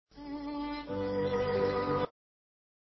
El intendente de Canelones, Marcos Carámbula, fue entrevistado en el programa